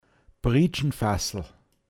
pinzgauer mundart
Jauchefass Priitschnfassl, n.